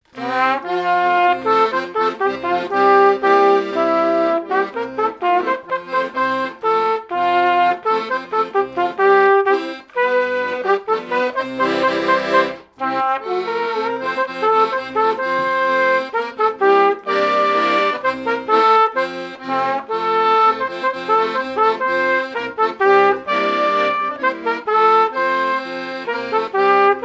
airs de danses issus de groupes folkloriques locaux
Pièce musicale inédite